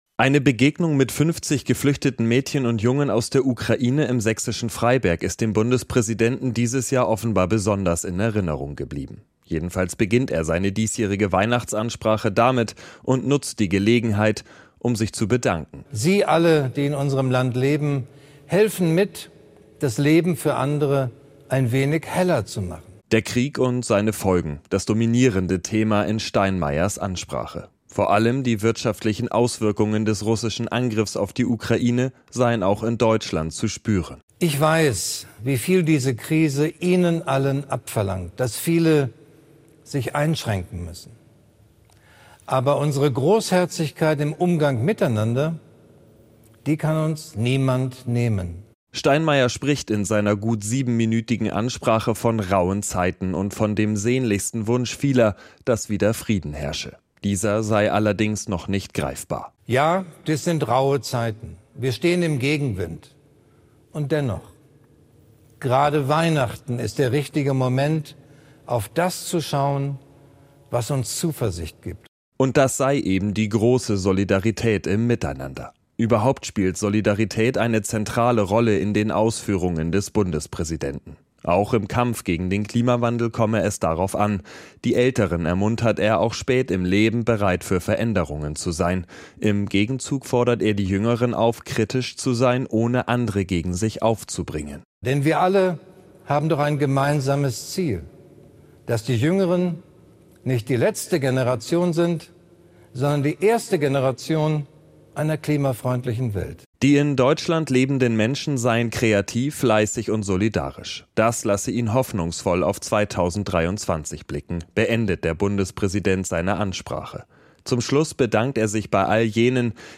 Weihnachtsansprache von Bundespräsident Steinmeier